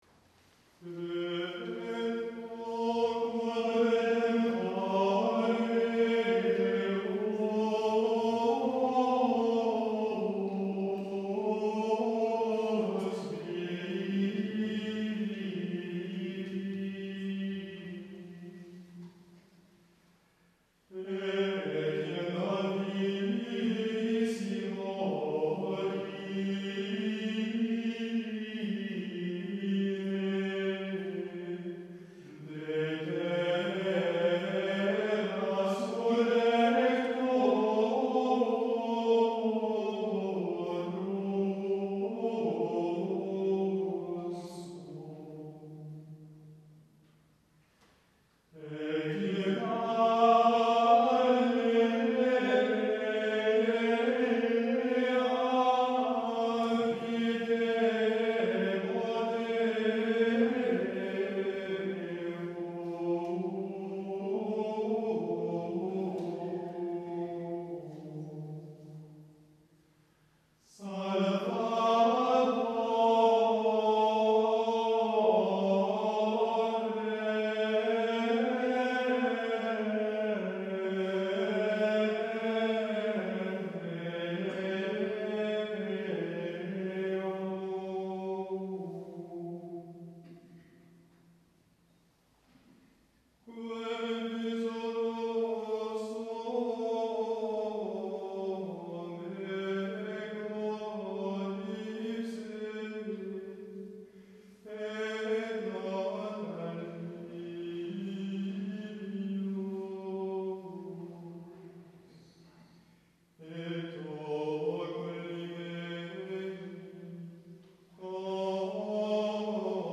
Extraits du concert donné le 25 novembre 2007 à l'église d'Hermance : "Officium Defunctorum - Office des Défunts", programme polyphonique en deux pans, serti, de part et d'autre, de pièces grégoriennes de l'office des morts.
prise de son artisanale !
3 - Credo quod Redemptor - répons grégorien - 3:01